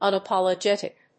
/`ʌnəpὰlədʒéṭɪk(米国英語), ˌʌnʌˌpɑ:lʌˈdʒetɪk(英国英語)/